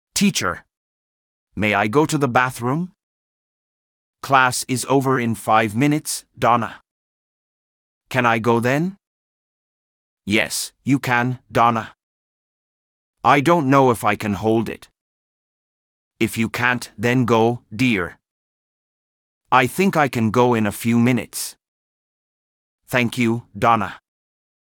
İngilizce Türkçe Diyalog – Tuvalete Gitmek